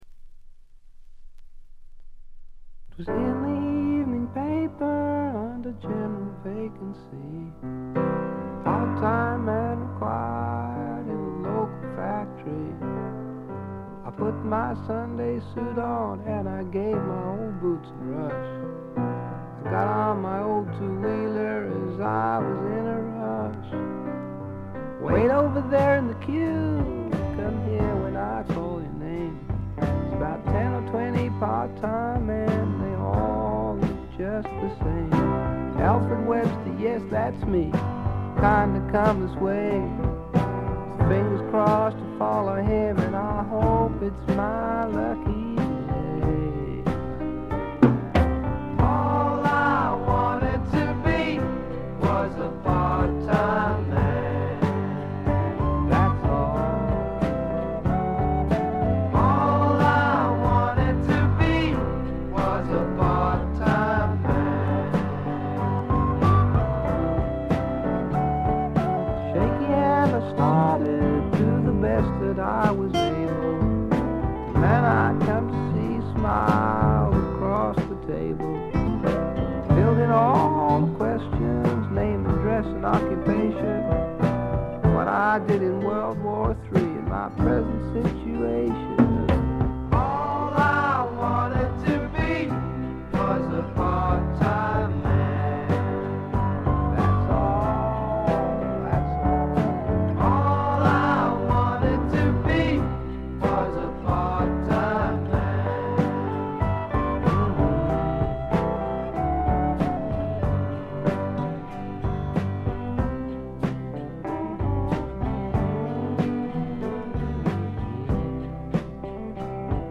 試聴曲は現品からの取り込み音源です。
Recorded at Maximum Sound, I.B.C. Studios